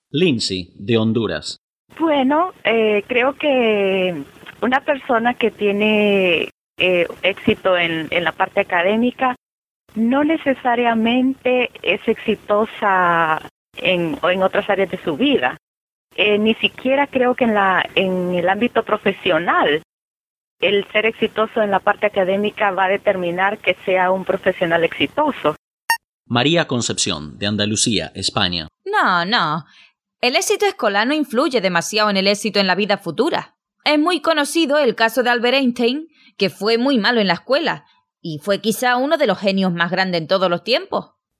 EL EXITO: Entrevista #2
(Success: Interview #2)
Recording: 0027 Level: Advanced Spanish Variety: Spanish from Honduras Spanish from Spain